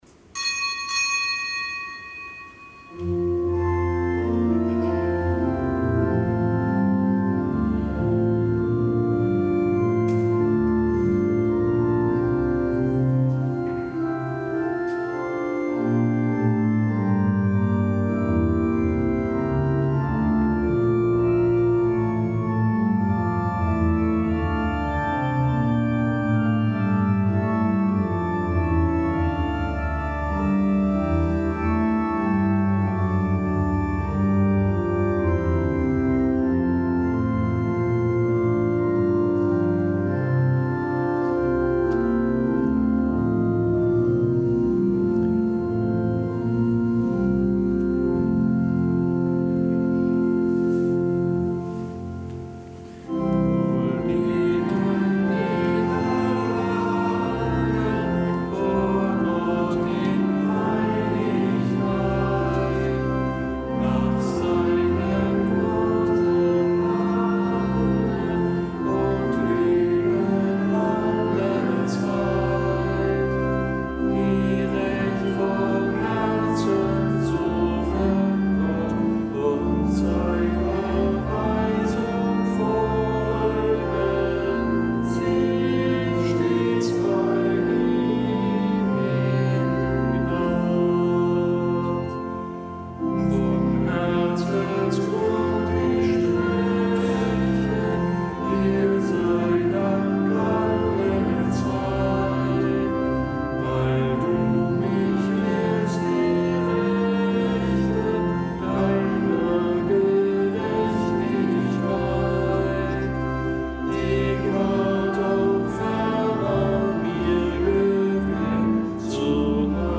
Kapitelsmesse aus dem Kölner Dom am Gedenktag des Heiligen Bruno, Bischof von Köln.